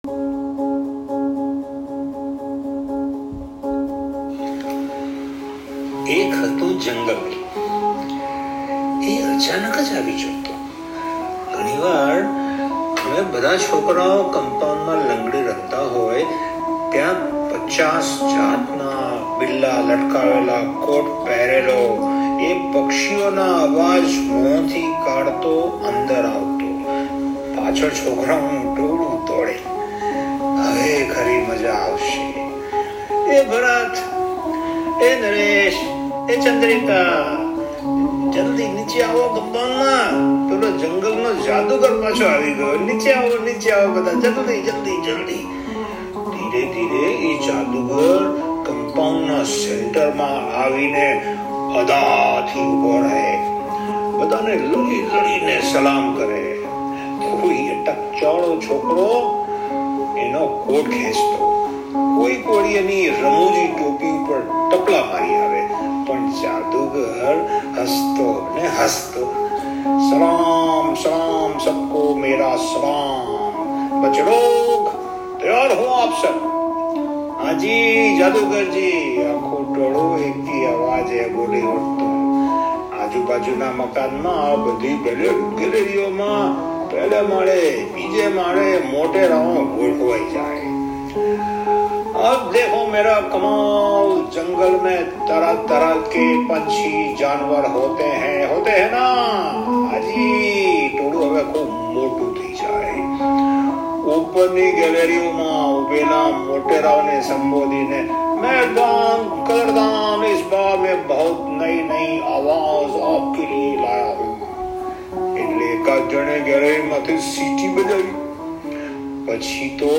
Listen to the new audio file above with my music embedded in it.